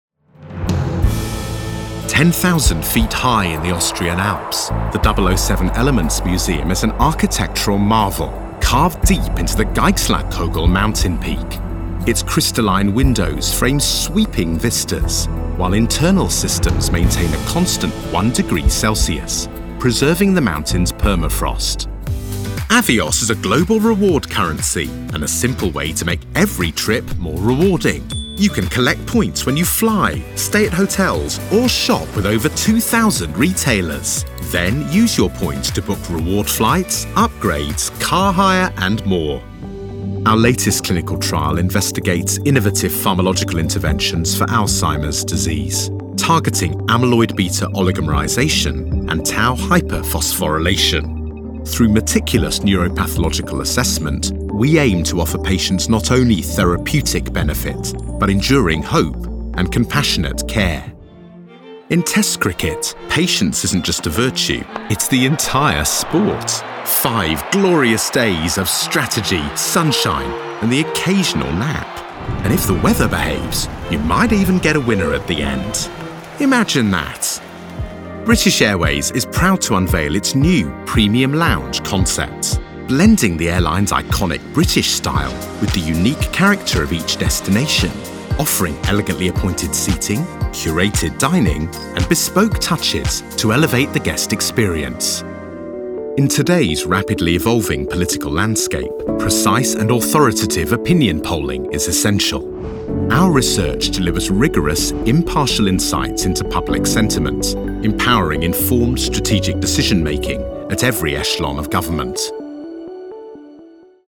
MALE VOICE OVER TALENT